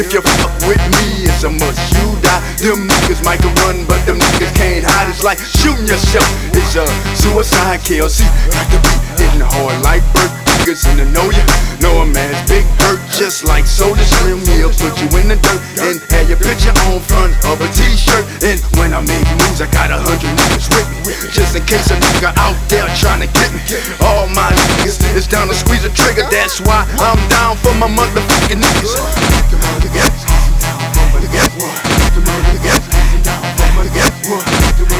Жанр: Хип-Хоп / Рэп
Hip-Hop, Rap, Dirty South, West Coast Rap, Gangsta Rap